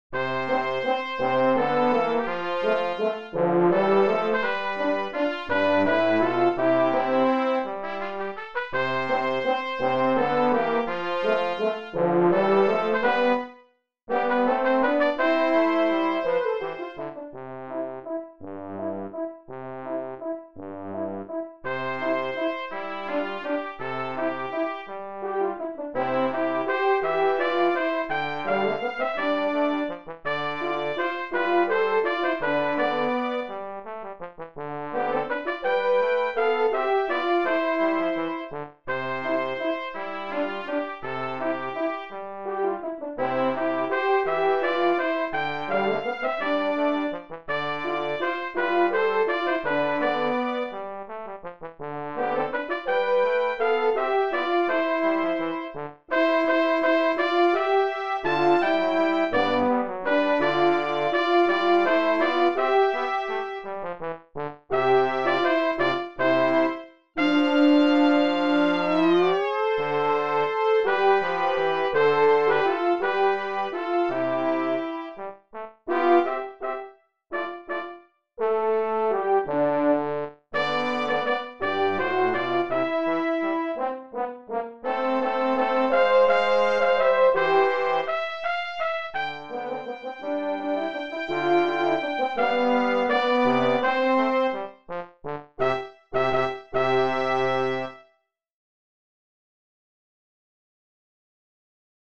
Brass Trio
traditional Mexican song
(in Bb)